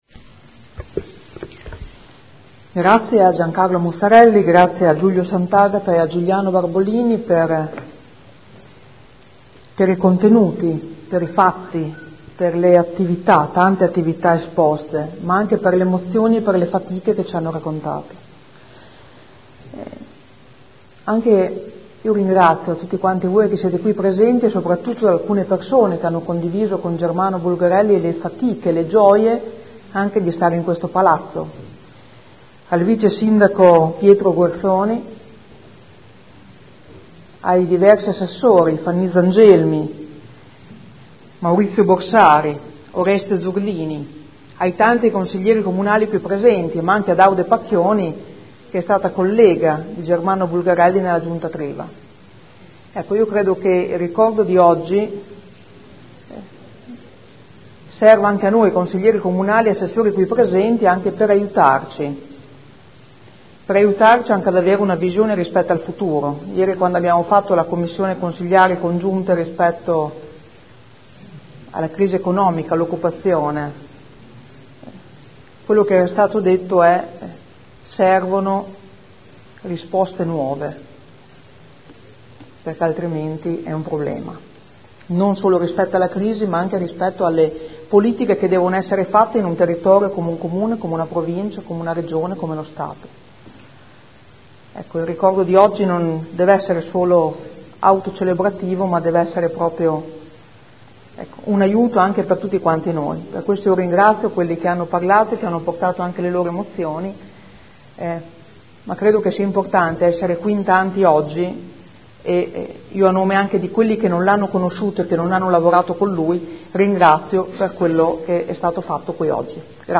Seduta del 2 luglio. Commemorazione del Sindaco Germano Bulgarelli ad un anno dalla scomparsa. Conclusioni del Presidente del Consiglio